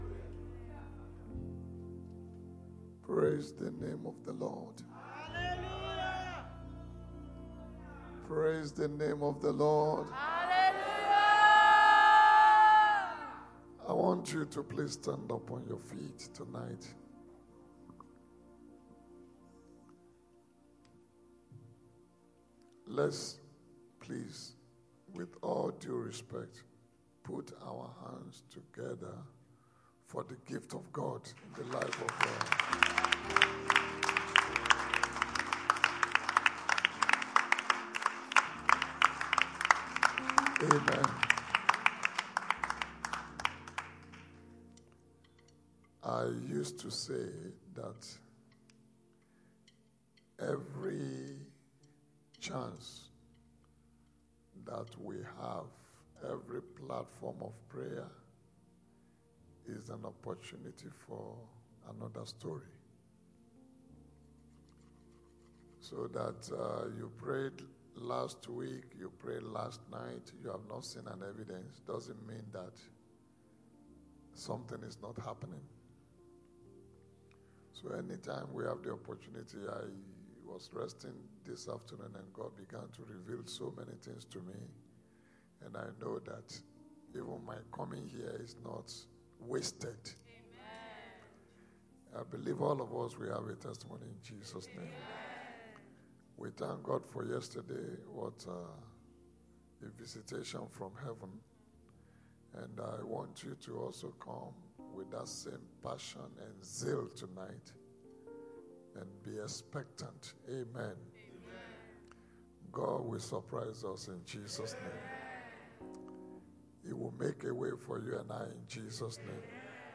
Anniversary Day 2: Holy Communion Service
Service Type: Sunday Church Service